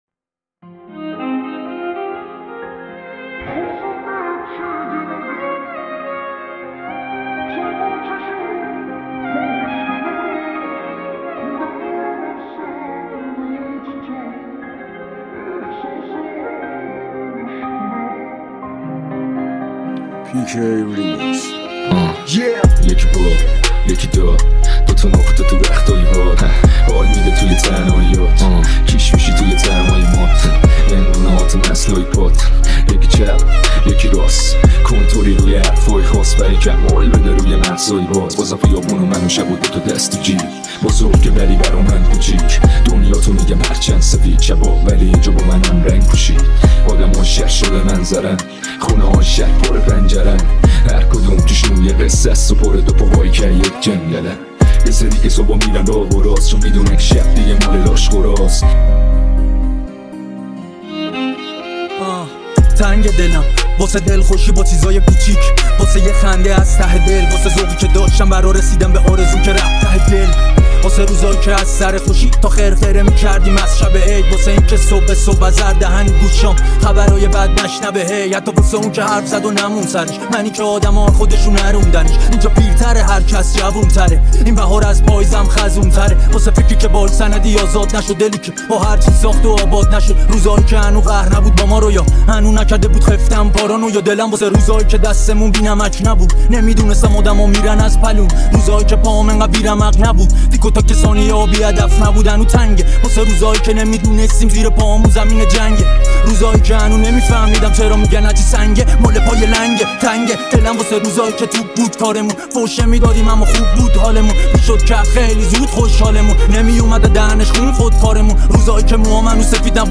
اهنگ رپ رپفارسی